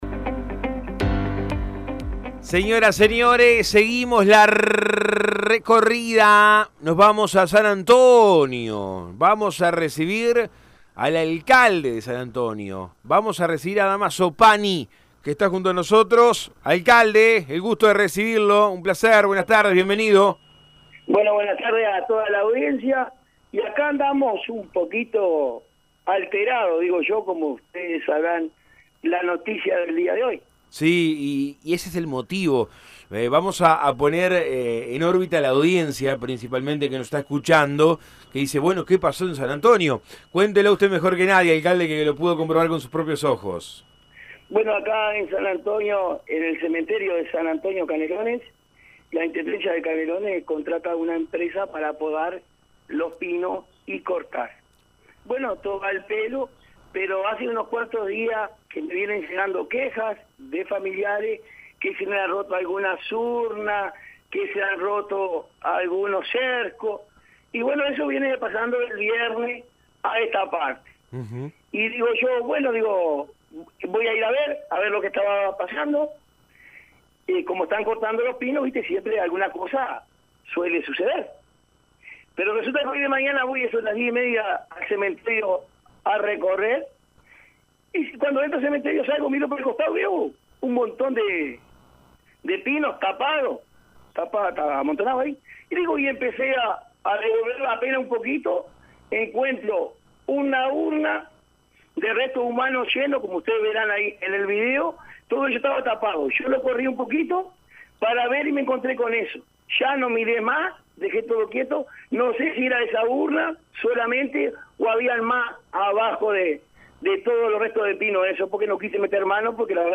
Esta mañana el Alcalde de San Antonio detectó y filmó urnas destrozadas y restos tapados con pinos. Ahora el alcalde Damaso Pani habló en Todo Un País sobre este hallazgo y cuales son las medidas que se tomarán. Pani dijo que esto se dio luego de que la comuna canaria contratará a una empresa para cortar los pinos que están dentro del predio.